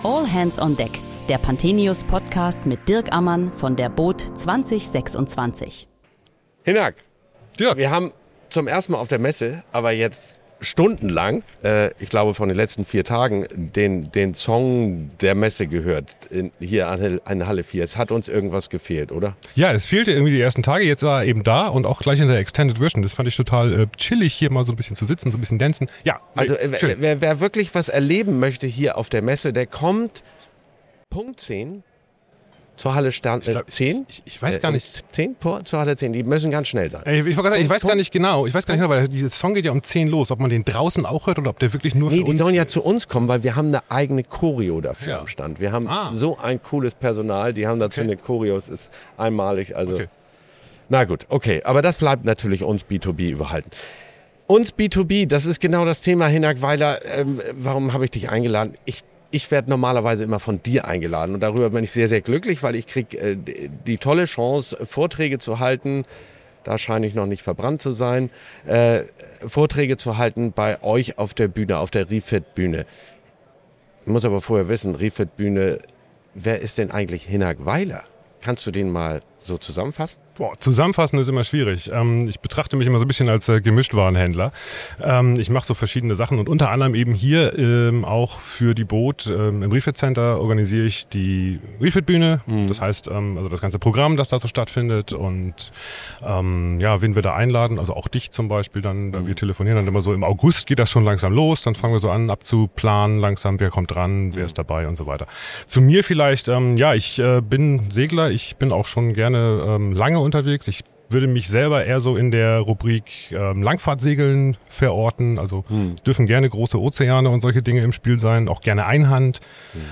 Während der boot Düsseldorf 2026 sprechen wir täglich mit Gästen aus der Branche über aktuelle und kontroverse Themen rund um den Wassersport.